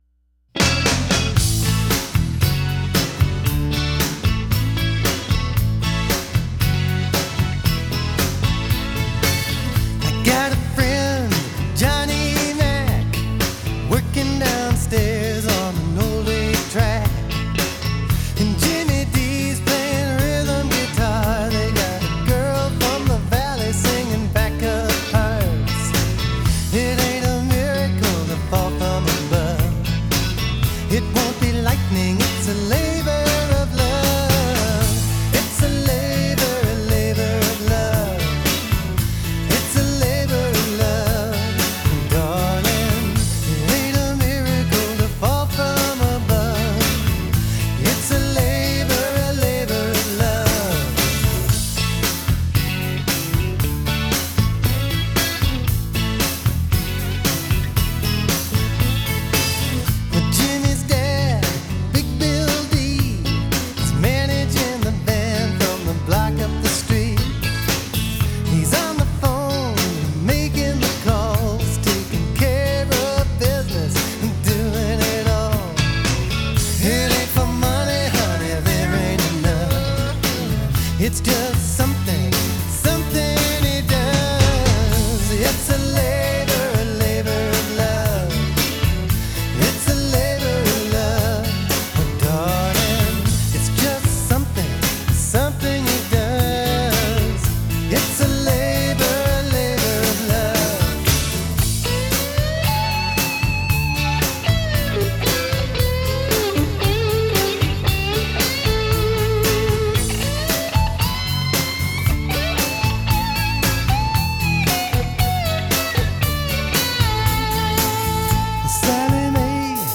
Pop / RockLove/RelationshipStories/HistoricalUp Tempo